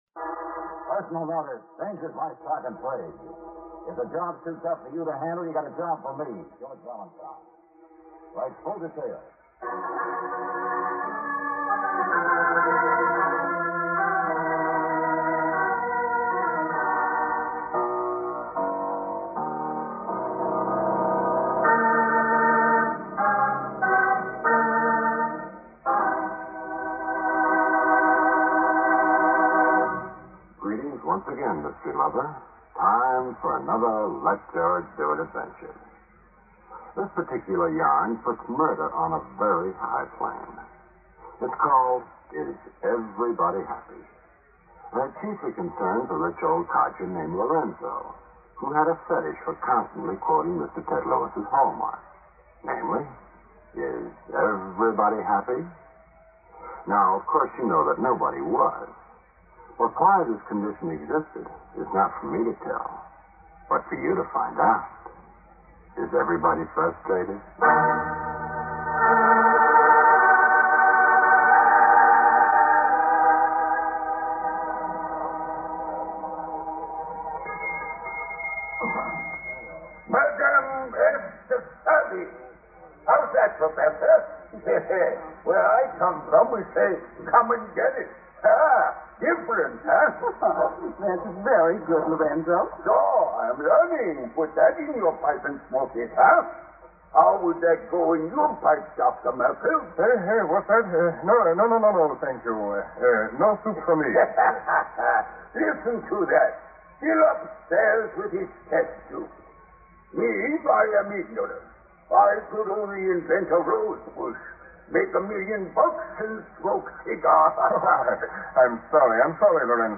Let George Do It Radio Program
starring Bob Bailey